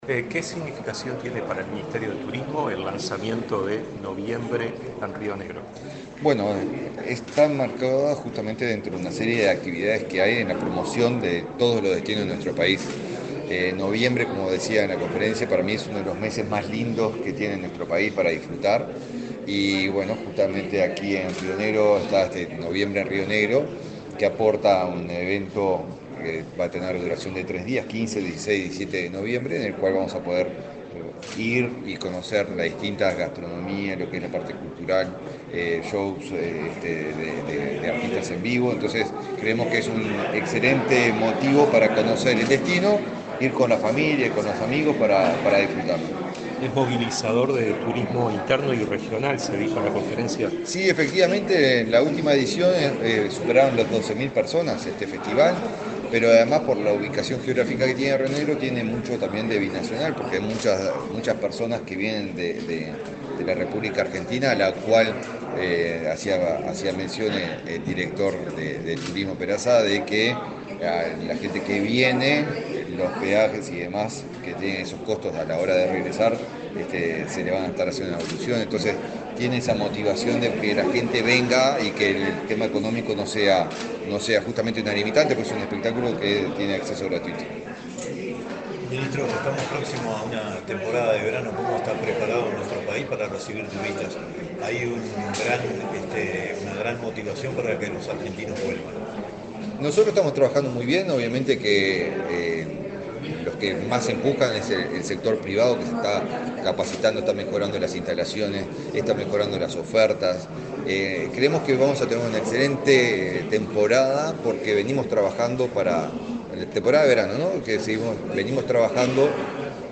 Declaraciones del ministro de Turismo, Eduardo Sanguinetti
El ministro de Turismo, Eduardo Sanguinetti, dialogó con la prensa, luego de participar en el lanzamiento de Noviembre en Río Negro, realizado este